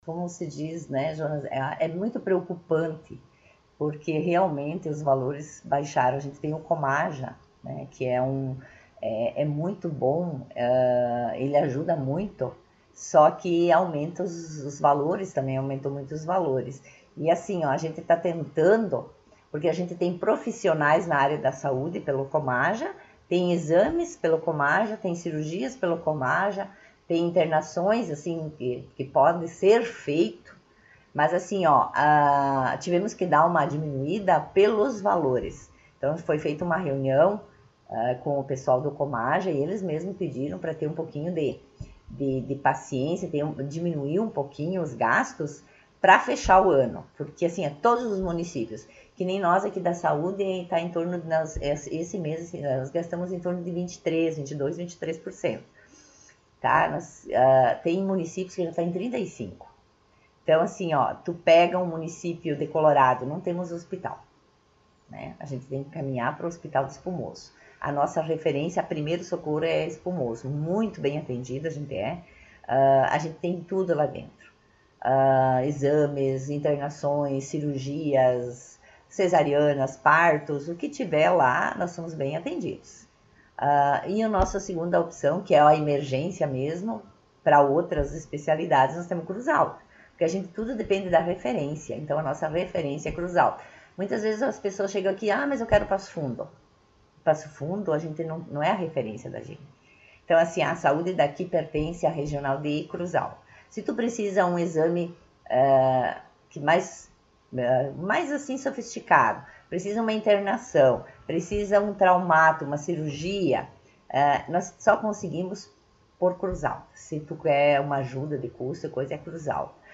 Vice-prefeita Marta Mino concedeu entrevista